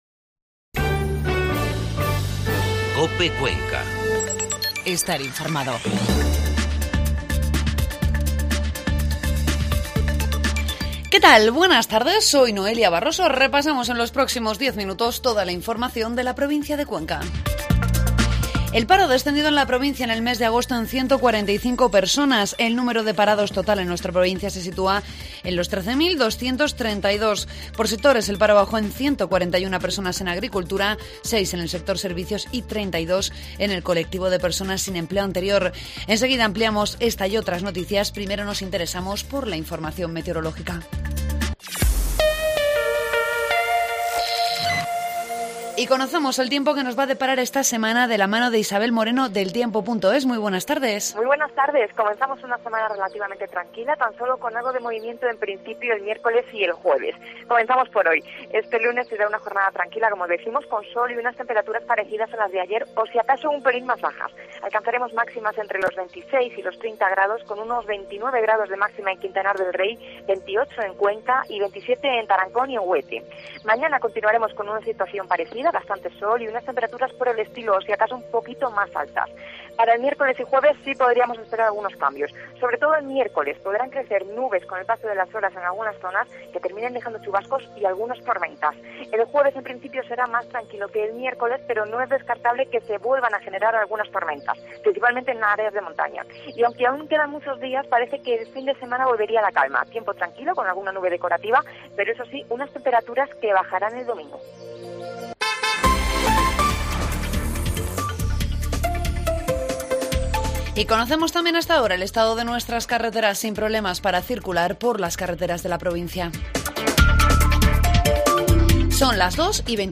AUDIO: Informativo mediodía